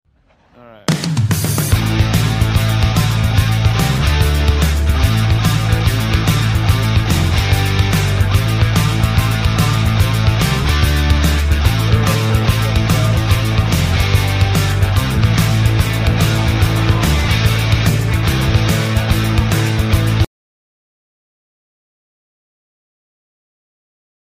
battle royale intro